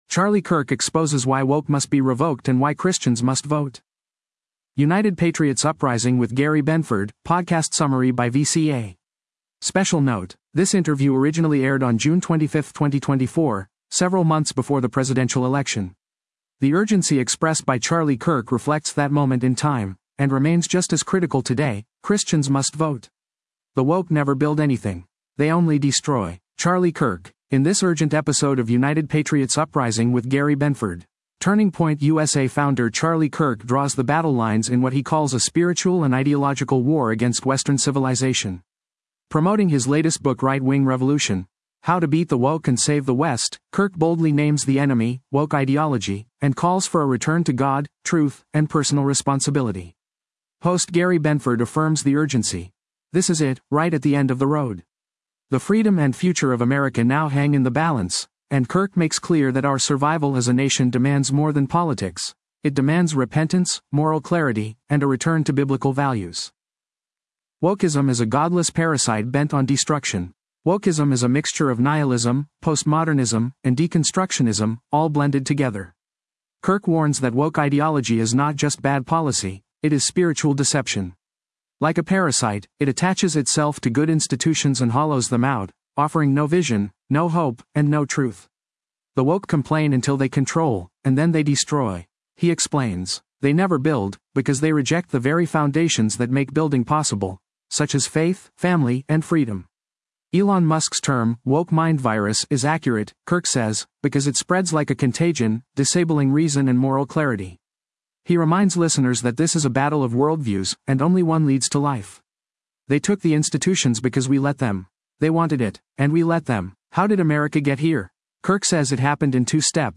Special Note: This interview originally aired on June 25, 2024, several months before the presidential election.